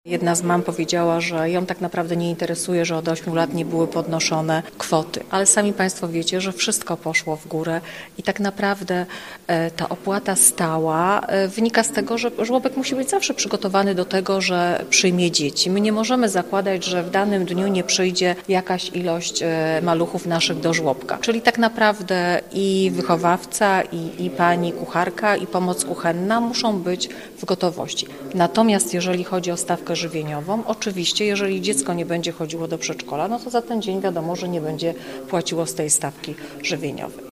– Podwyżka to skutek nowelizacji ustawy o opiece nad dziećmi w wieku do lat 3 , ale także ogólnego wzrostu cen- tłumaczyła wiceprezydent Małgorzata Domagała: